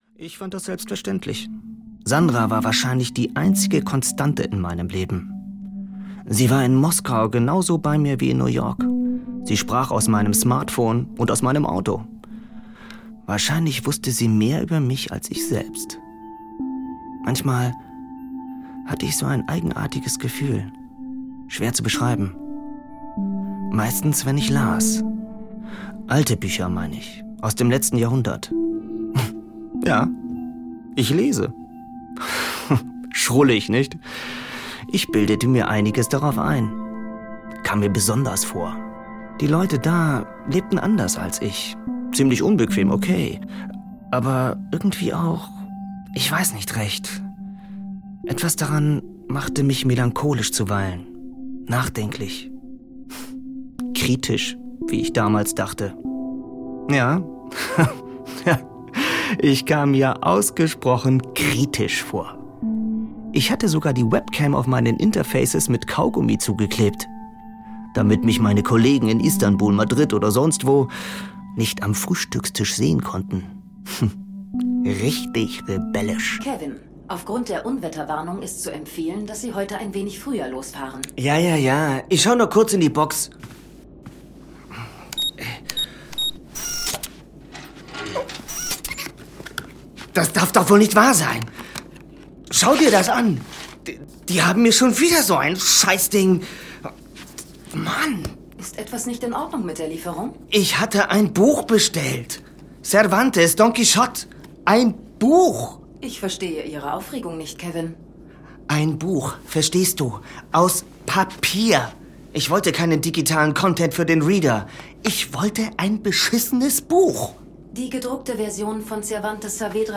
Navigator - Norman Liebold - Hörbuch